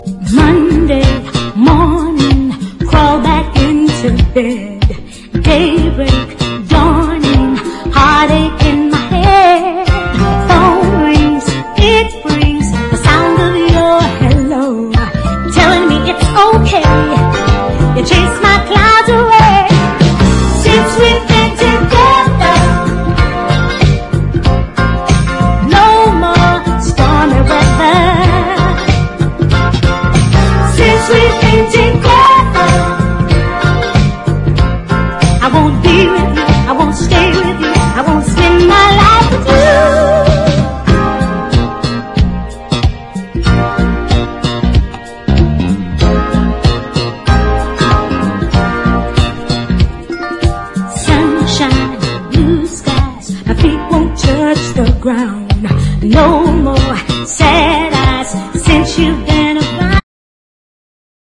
NEW WAVE DISCO
ゴージャスな女性コーラスとグルーヴィなベース・ラインがうねりまくるNEW WAVE DISCO FUNK超名曲
ごりごりのベース・ラインに跳ねるディスコ・ビート、ファンキーなカッティング・ギターが堪らない変態ディスコ・クラシック